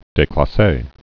(dāklä-sā)